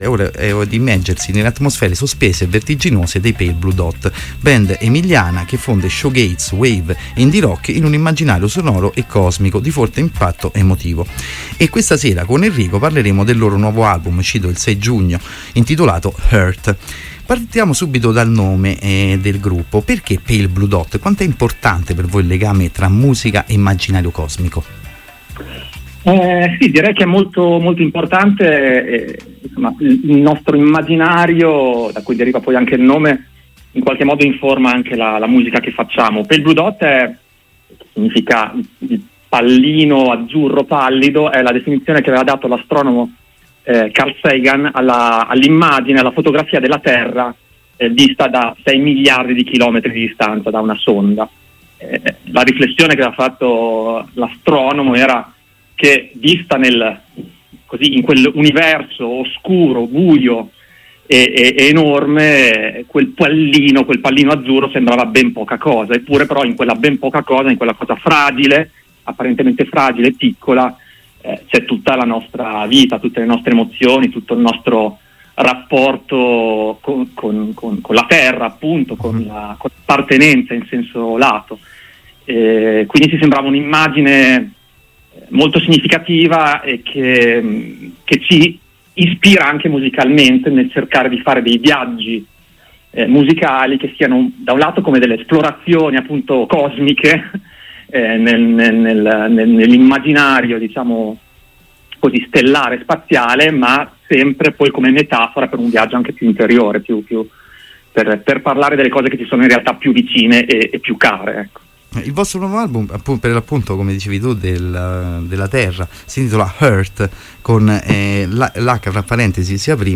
Intervista-Pale-Blue-Dot.mp3